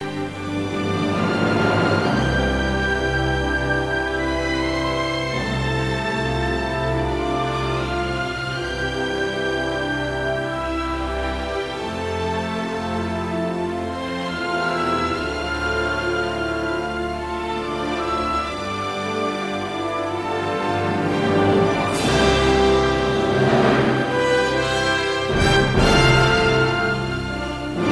Original Score